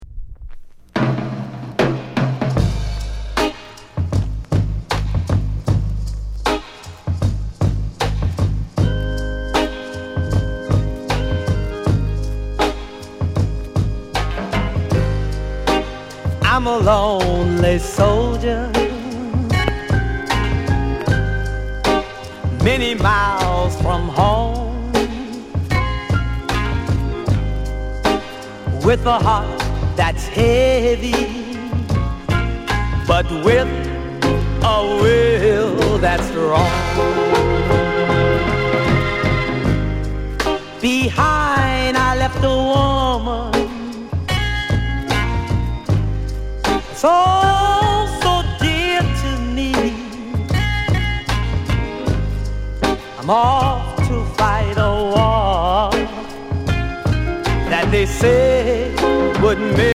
JAMAICAN SOUL